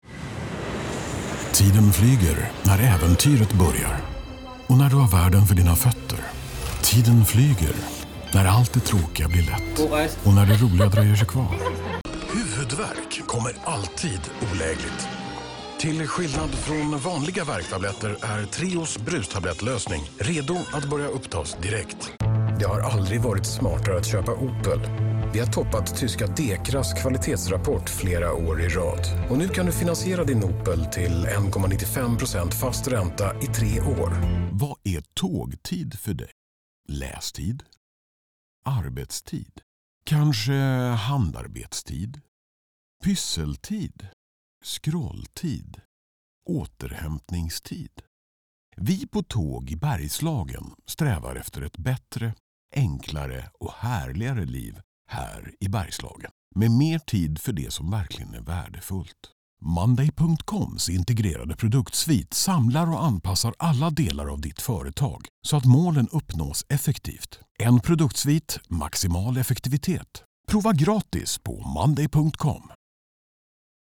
Male
Approachable, Authoritative, Confident, Conversational, Corporate, Deep, Energetic, Engaging, Versatile, Warm
European english with a slight swedish twang
Microphone: Austrian Audio OC18